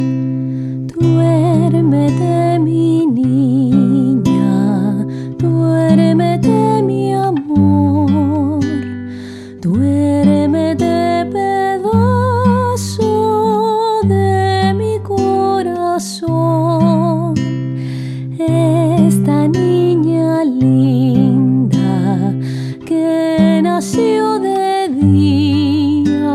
most beloved lullabies